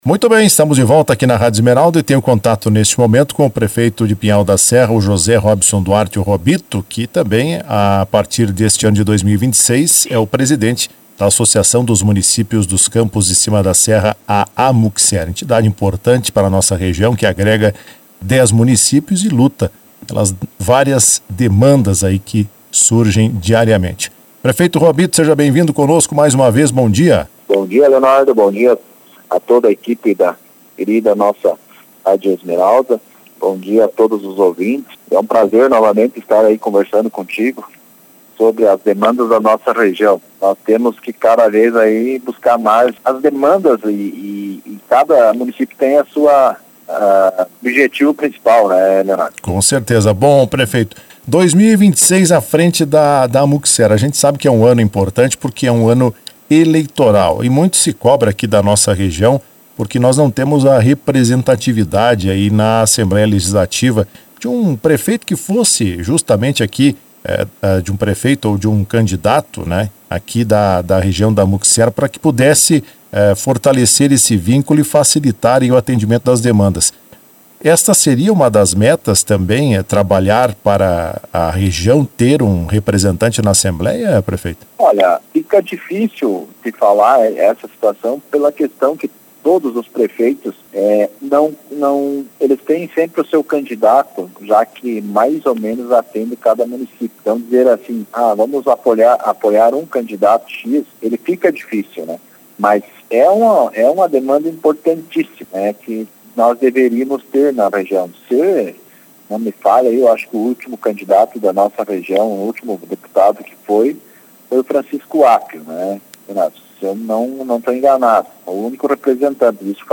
O prefeito de Pinhal da Serra e presidente da Amucser (Associação dos Municípios dos Campos de Cima da Serra) para o ano de 2026, José Robinson Duarte, participou do programa Comando Geral desta quarta-feira, 14.